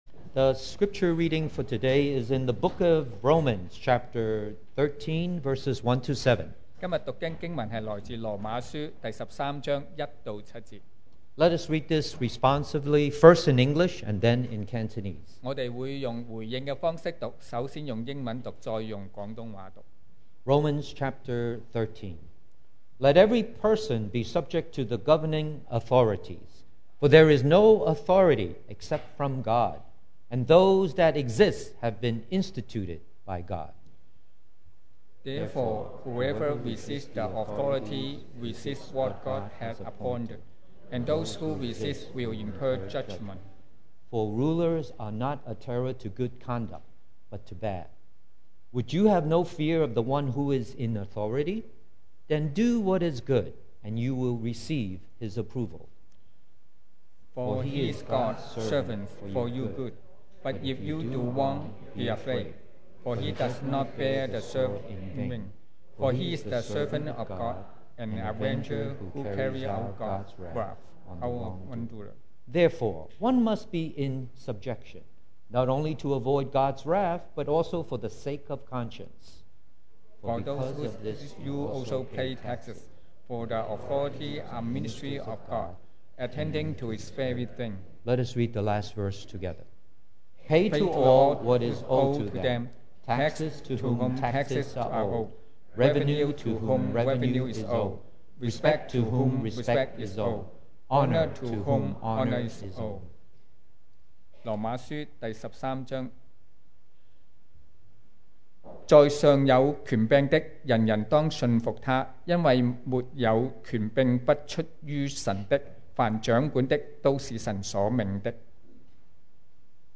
2019 sermon audios
Service Type: Sunday Morning